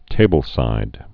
(tābəl-sīd)